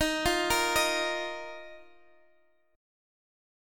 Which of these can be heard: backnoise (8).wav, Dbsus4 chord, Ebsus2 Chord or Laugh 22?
Ebsus2 Chord